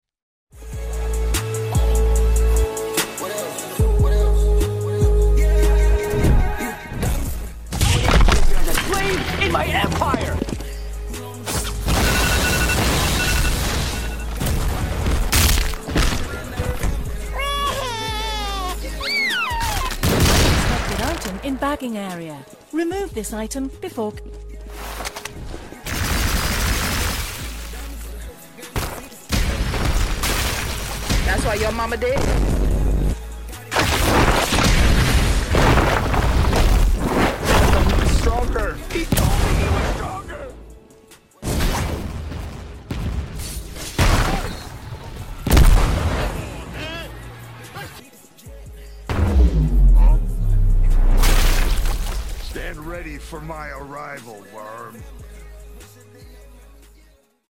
|| GET OUT KILL SOUND/ROBLOX ID || MOST HARDEST/TOXIC/BEST/FUNNIEST KILL SOUNDS II MIGHT POST A OLD KILL SOUND VIDEO I NEVER GOT TO UPLOAD👀|| DON'T FORGET TO SMASH THAT LIKE BUTTON!!!! // GAME: JUJUTSU SHENANIGANS